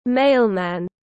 Mailman /ˈmeɪlmæn/